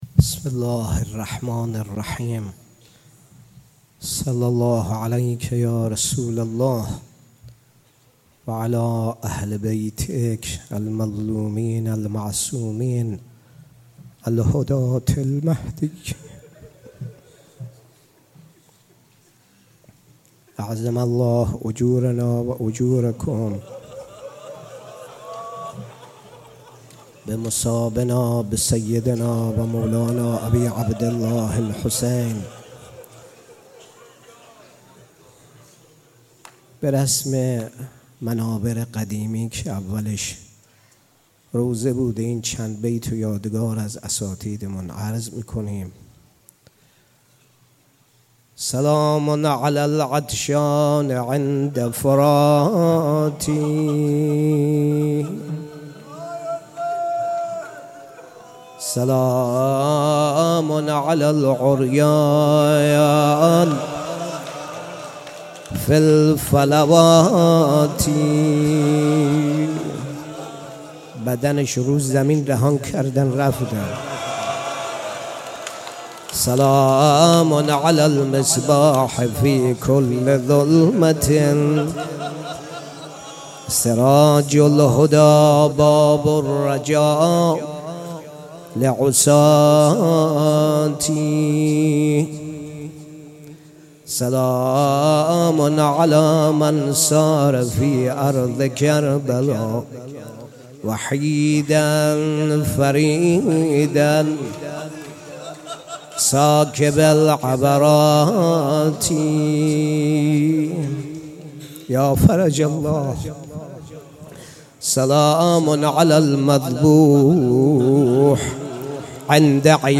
شب عاشورا محرم 96 - کربلای معلی - سخنرانی